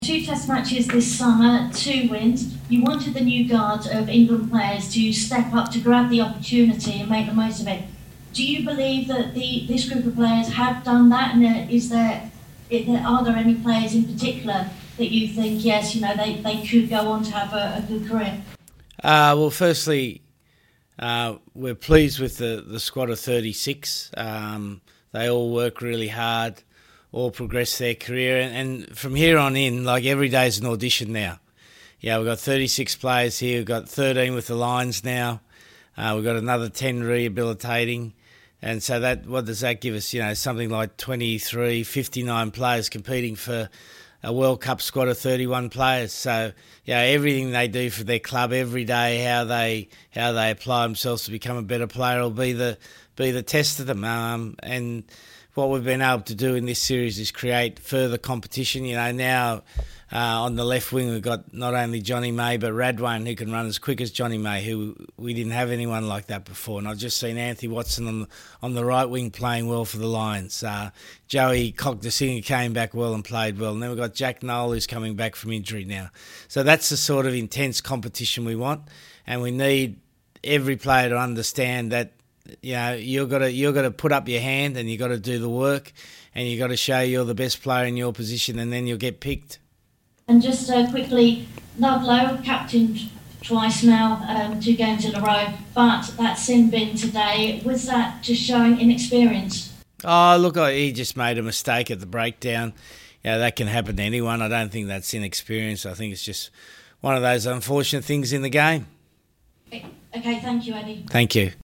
in the England v Canada post-match press conference.